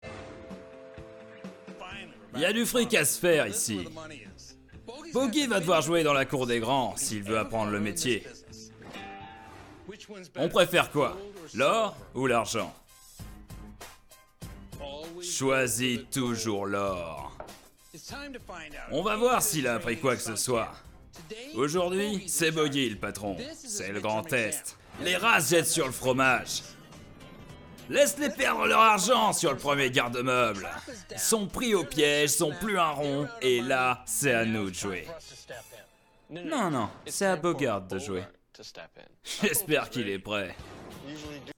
Extrait Dessin Animé (Team Fortress 2)
12 - 30 ans - Baryton Ténor